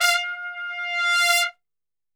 F 3 TRPSWL.wav